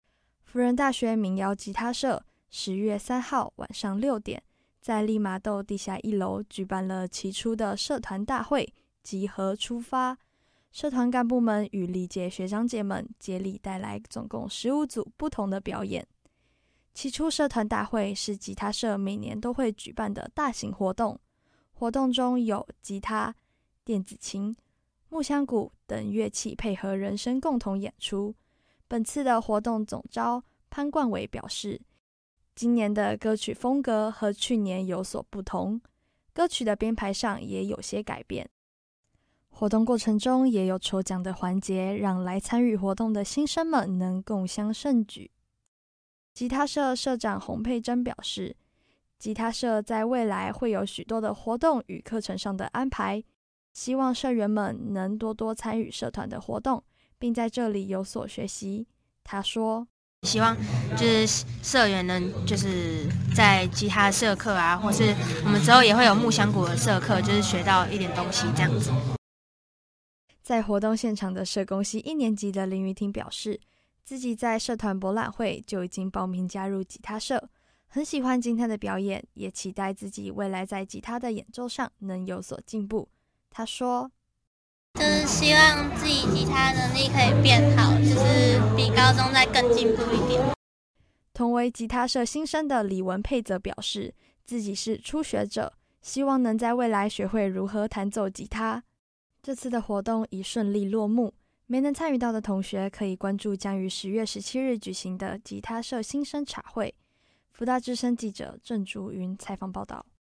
1081社—「吉合出發」 吉他社期初社團大會 Posted by 輔大之聲新聞部 on 2019-10-07 輔大吉他社在十月三號舉辦了期初社團大會「吉合出發」，除了有社團幹部們帶來的精彩表演，也有學長姐們回來助陣，活動吸引了許多同學參加，希望有更多喜歡音樂的新生們加入社團。